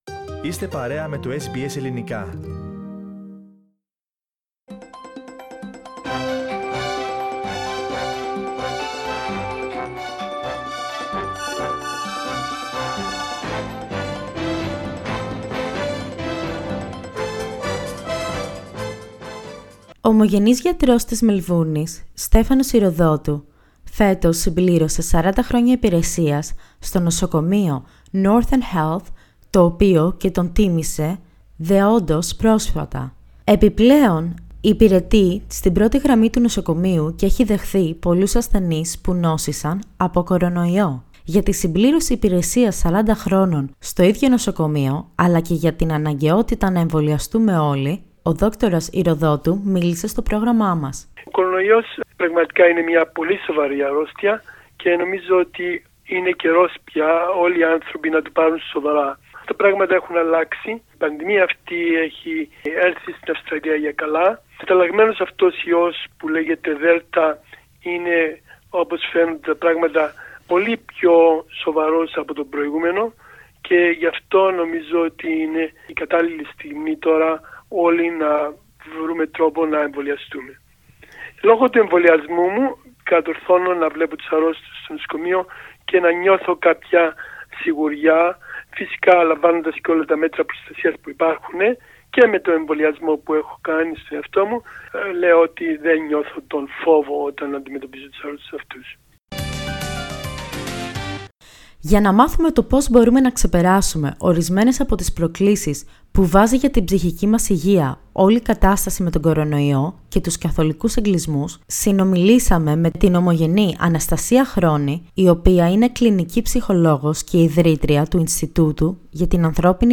Ο ομογενής γιατρός που τιμήθηκε για τα 40 χρόνια υπηρεσίας του, η Κλινική Ψυχολόγος που μιλά για τις προκλήσεις που βιώνουμε λόγω της πανδημίας, ο ομογενής που χρειάστηκε να ταξιδέψει στην πατρίδα και ο ναυτικός που έγραψε βιβλίο με την ιστορία της ζωής του ήταν μερικά από τα πρόσωπα που μας μίλησαν την περασμένη εβδομάδα.